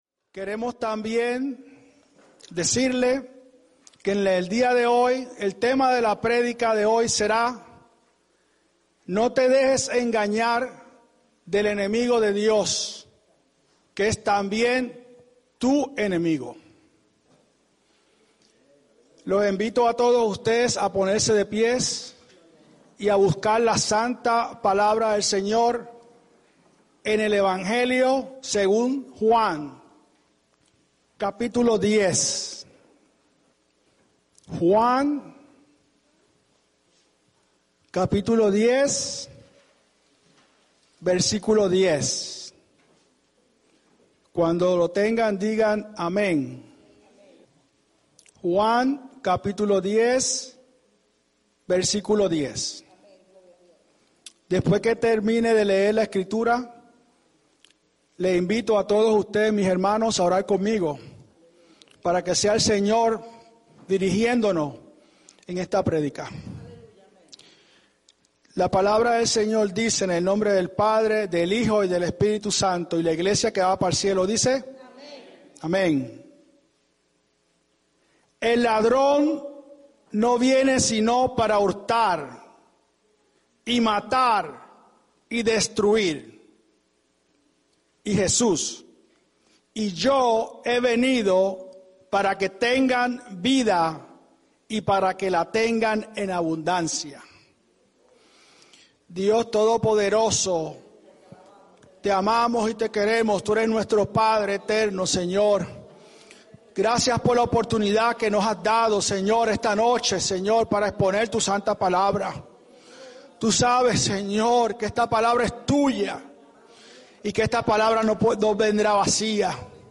No te dejes engañar por el enemigo de Dios Predica